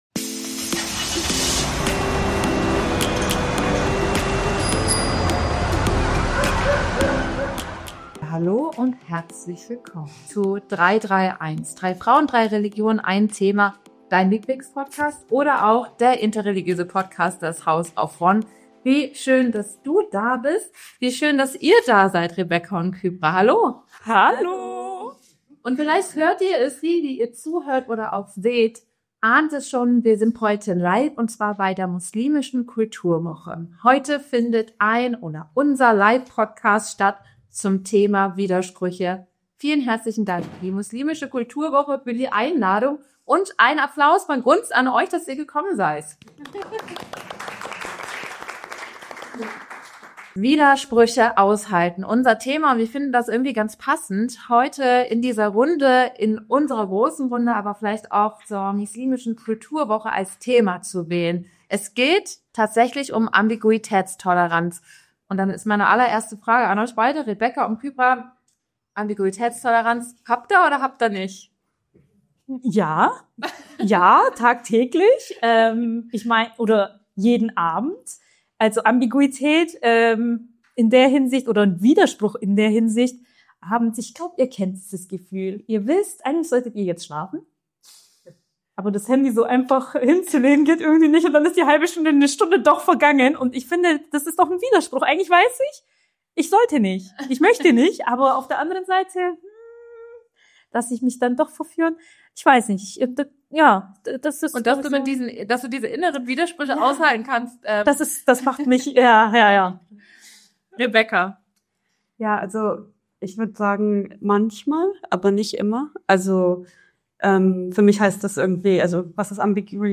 Diese Folge haben wir live vor Publikum im Rahmen der Muslimischen Kulturwoche am 29. September in Berlin aufgenommen.